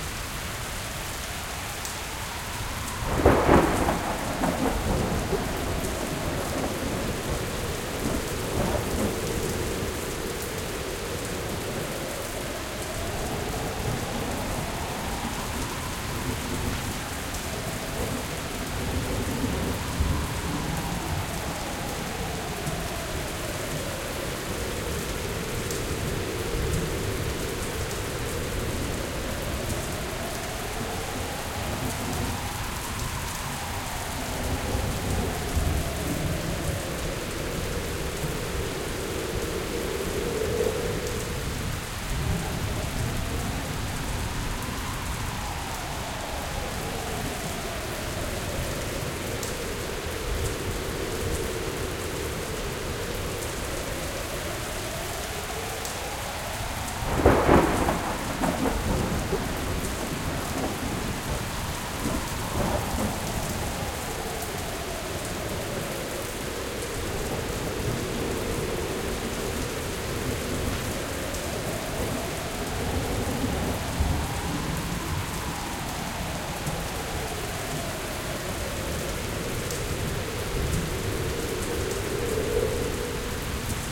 Mayak_dayrain.ogg